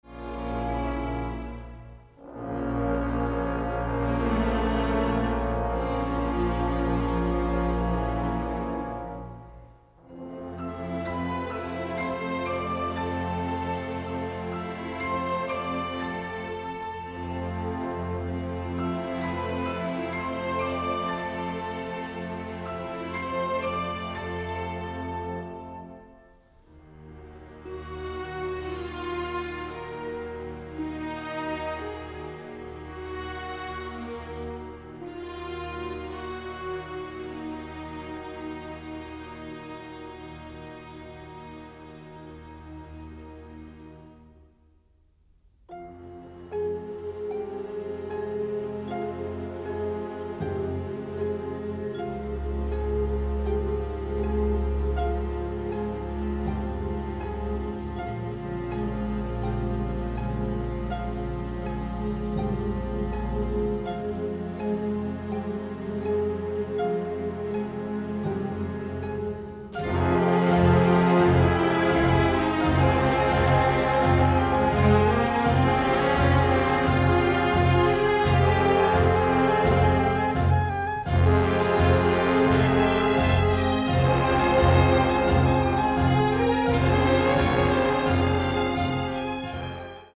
thrilling, often romantic score